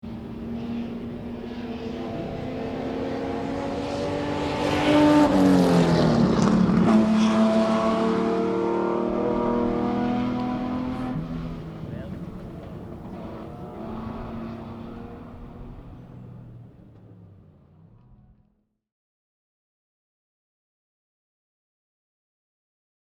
Zu diesem Anlass wird die Strecke in der Sarthe während 45 Minuten für historische Rennfahrzeuge geöffnet, ein Moment, der viele Le Mans Enthusiasten erfreut.
Ferrari 250 LM #13 von Le Mans Legend 2011